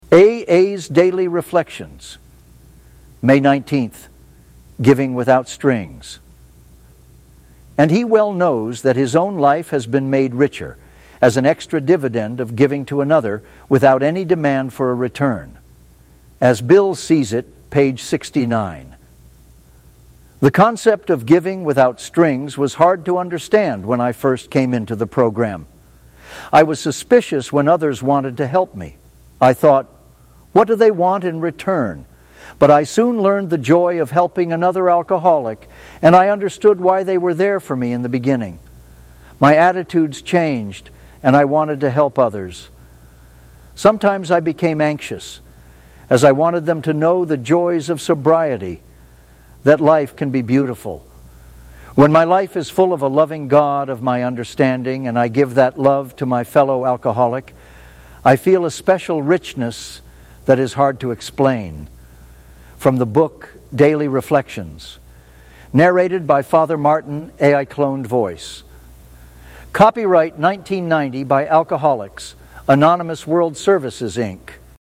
Daily Reflections